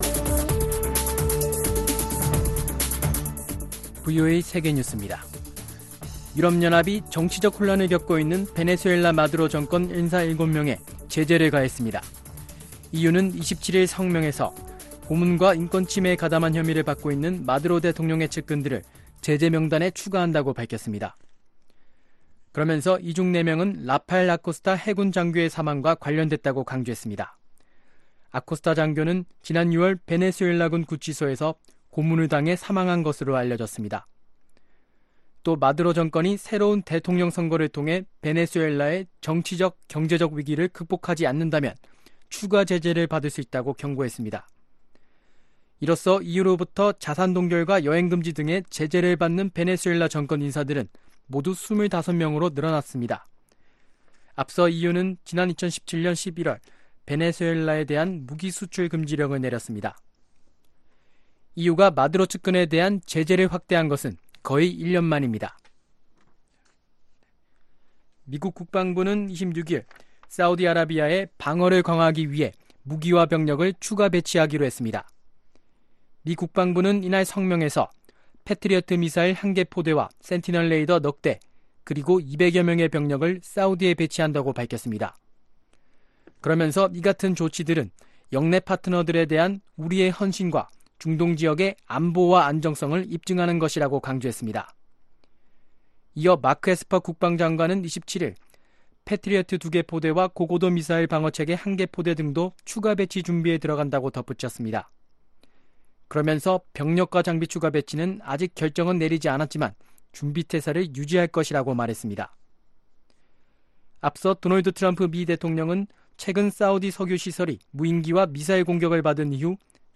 VOA 한국어 아침 뉴스 프로그램 '워싱턴 뉴스 광장' 2019년 9월 28일 방송입니다. 마이크 폼페오 미 국무장관은 북한 측과 마주 앉을 수 있는 구체적인 날짜를 아직 잡지 못했다고 밝혔습니다. 미국과 한국의 주요 안보 현안을 논의하는 고위급 국방협의체 (KIDD)회의가 26일부터 이틀간 서울에서 열렸습니다.